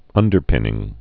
(ŭndər-pĭnĭng)